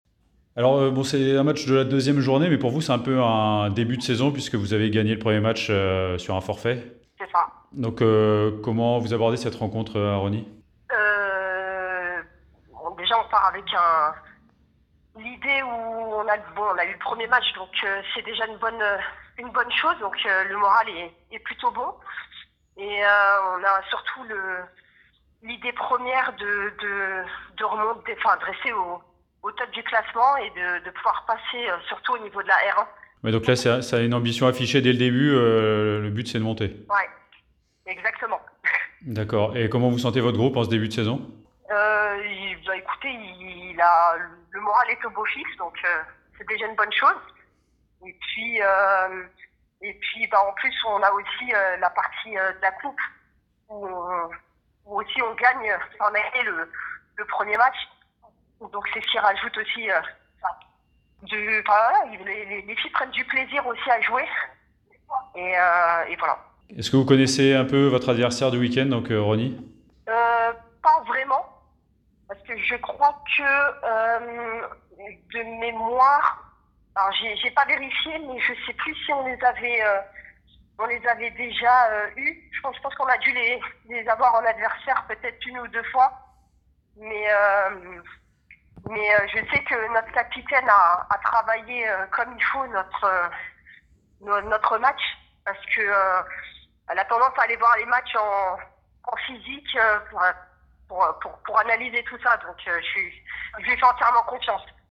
L’interview en intégralité